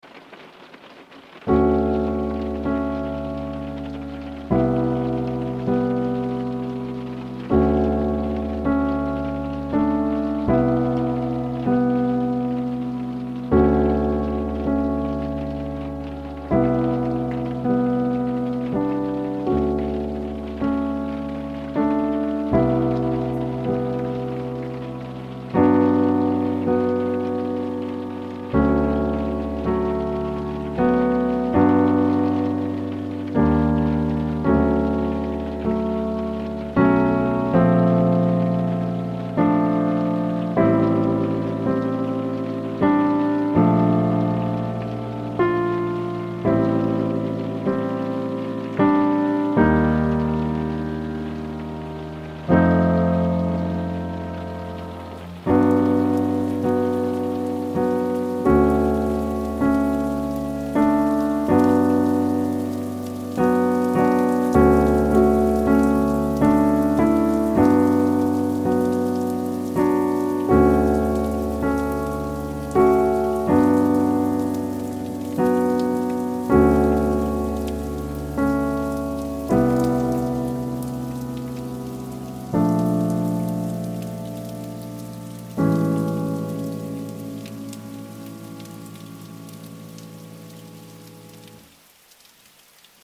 Brazilian storm reimagined